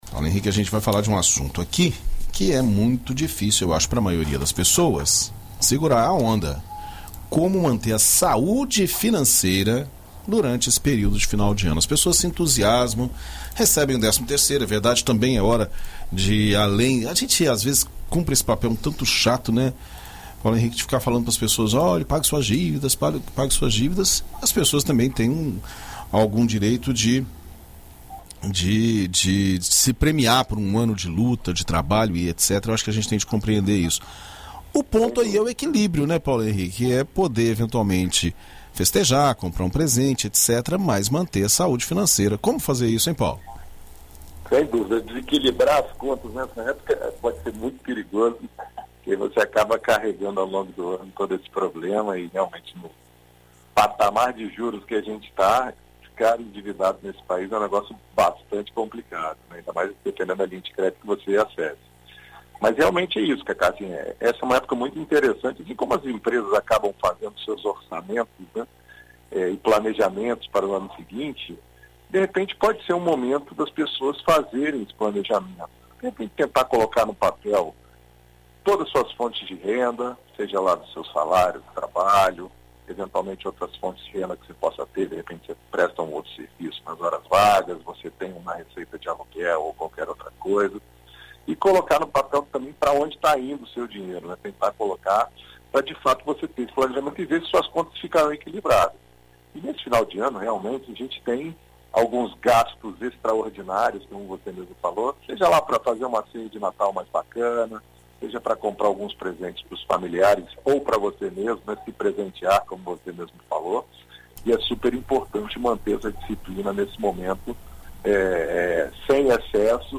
Na coluna Seu Dinheiro desta terça-feira (07), na BandNews FM Espírito Santo